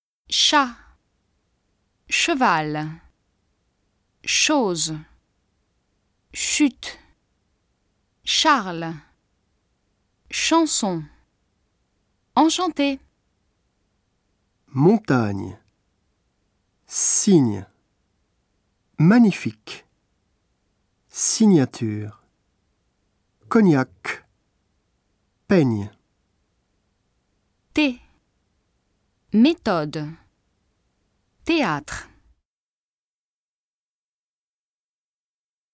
トップページ > 綴り字と発音 > ３ 綴り字と発音 ３ 綴り字と発音 4)子音 綴り字 発音記号 例 発音上の注意 ch [ʃ] ch at ch eval ch ose ch ute Ch arles ch anson En ch anté 例外：chaos[kao] technique[tɛknik] écho[eko] shの綴りは外来語のみ。
07 Prononciation - 3_4.mp3